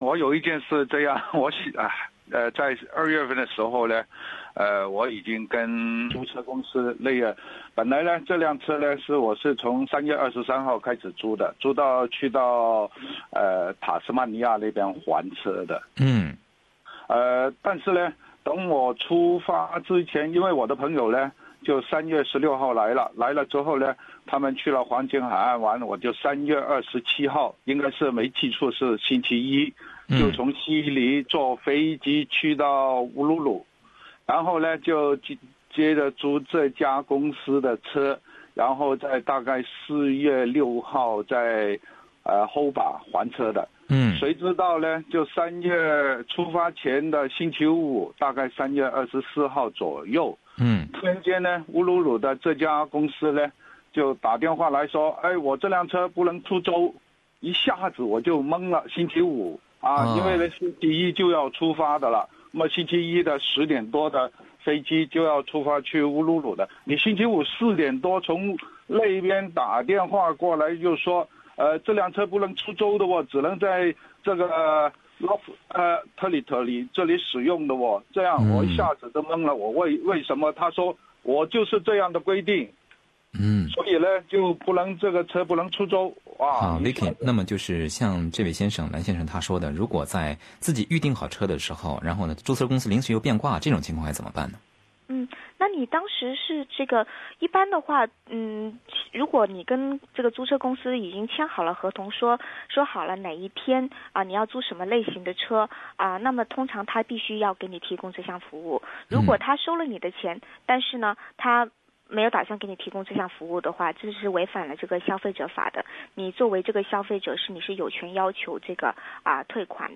租车公司违约怎么办？——《消费者权益讲座》热线问答